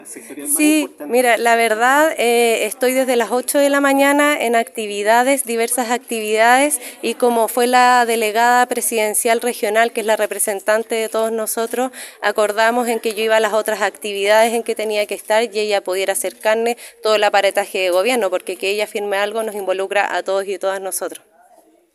En tanto, pasado el mediodía, en el auditorio de la sede Serena de la Universidad de Los Lagos en Puerto Montt, se realizó un punto de prensa luego del acto de conmemoración del Día Internacional de la Mujer, evento organizado por la Seremi de la Mujer y de la Equidad de Género, con el apoyo de Segegob Los Lagos. ANEF margina a Seremi de la Mujer en acto del 8M en la Región de Los Lagos En la ocasión, se le consultó a la Seremi Macarena Gré las razones de su marginación en la Constitución Comisión Regional por el Buen trato y la no discriminación por razones de género que se había realizado durante la mañana junto a otras autoridades regionales.